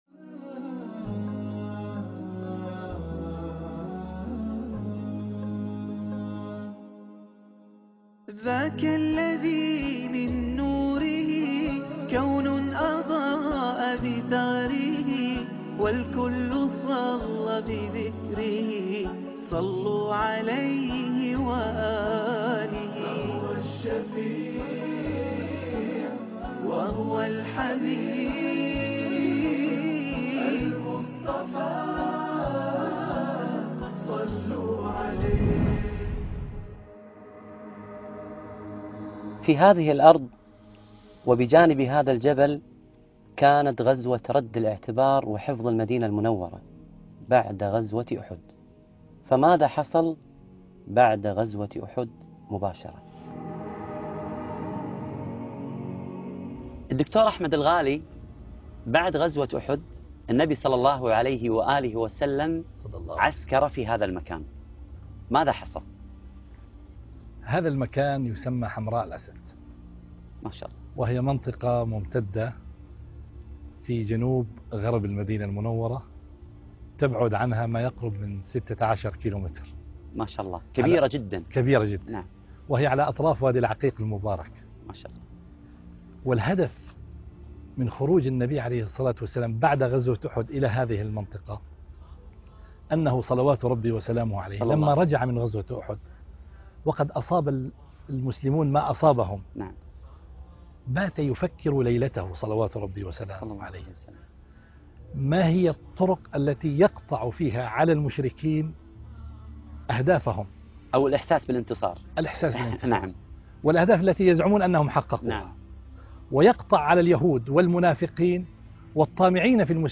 الحلقة 14- قدوة 2 - حمراء الأسد - القاريء فهد الكندري